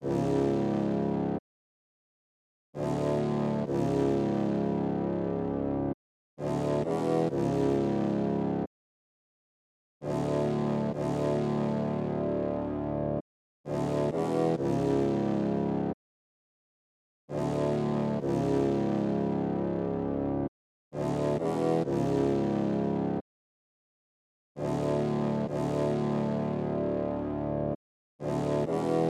南方黄铜
描述：漂亮的黄铜 在南方风格的节拍中使用
Tag: 66 bpm Hip Hop Loops Brass Loops 4.90 MB wav Key : Unknown